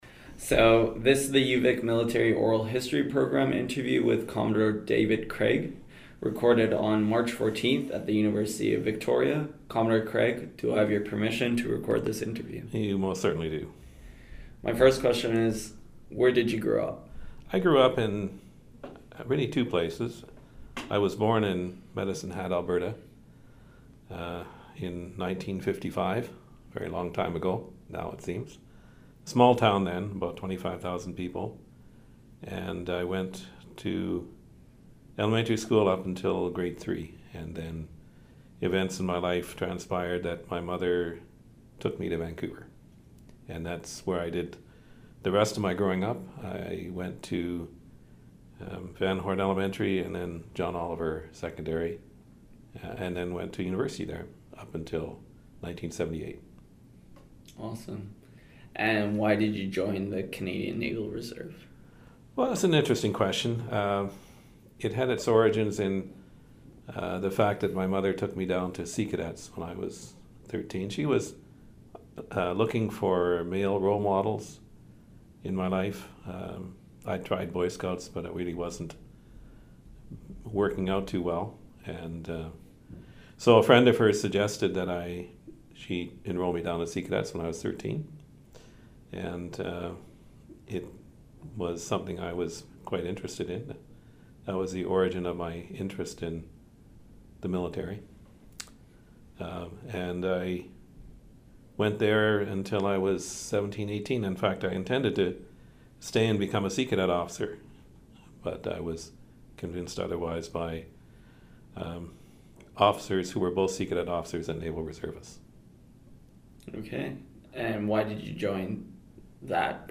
Interview took place on March 14, 2019 in Victoria, B.C.